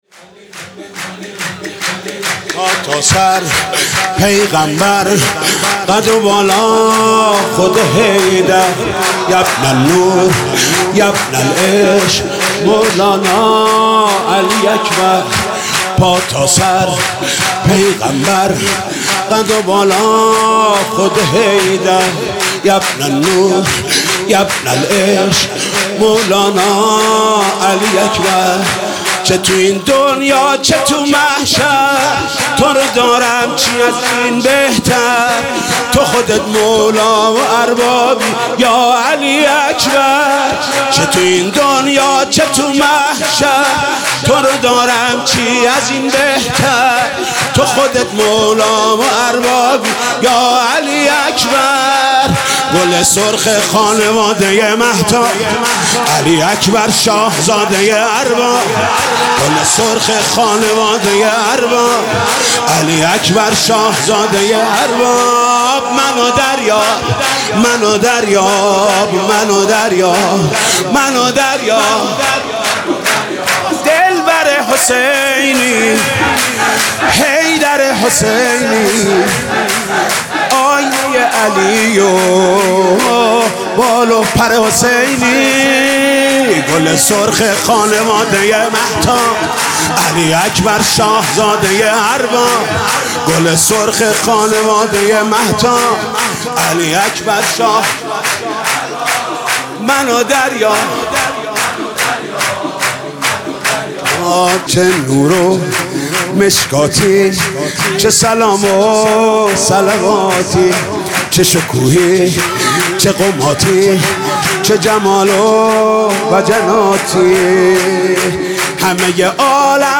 سرود: پا تا سر پیغمبر قد و بالا خود حیدر